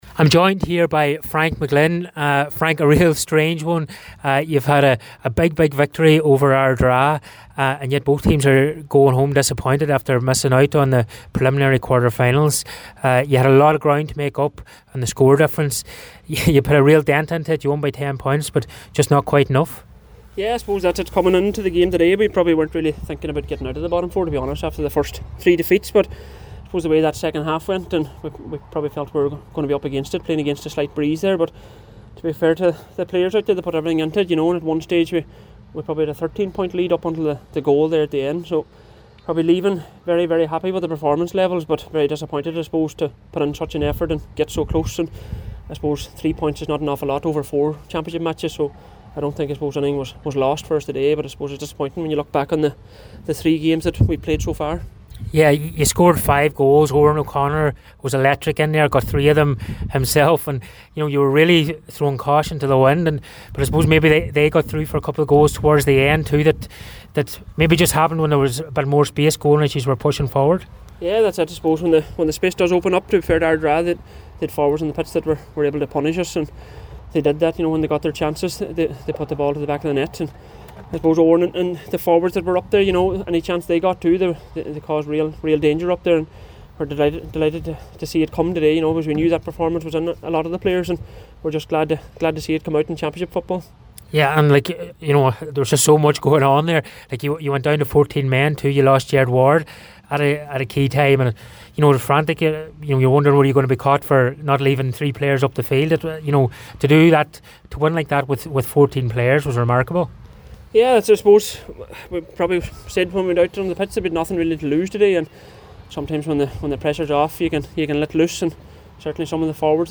after last night’s game